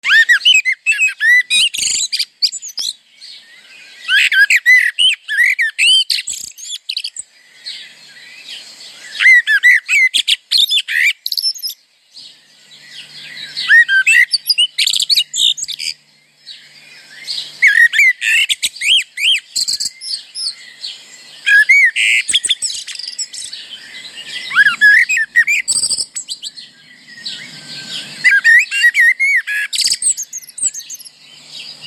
Mierla (Turdus merula)
• Cântecul ei e vesel ca un fluierat – simplu, dar mereu plin de bucurie. • Cântă de obicei de sus, din vârful copacilor, unde o auzi ușor, dar o vezi greu. • Adoră să cânte după ploaie, când pădurea e udă și liniștită.
Mierla.mp3